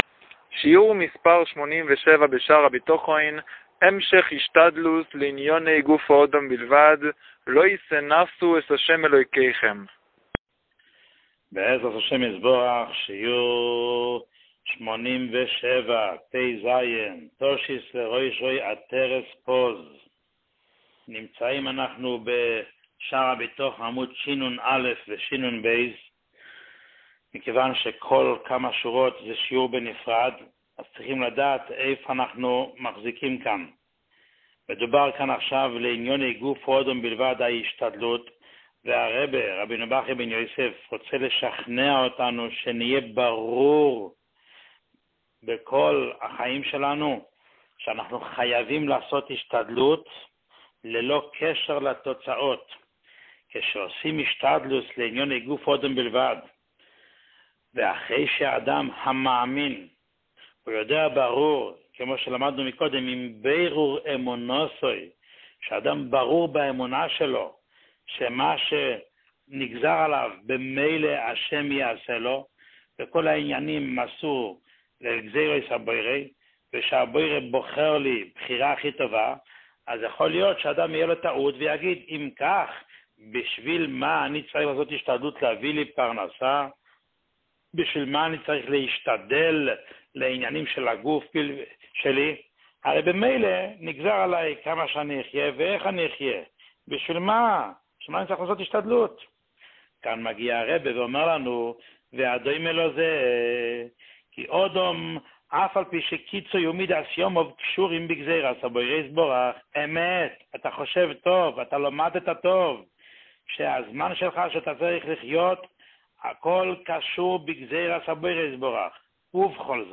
שיעור 87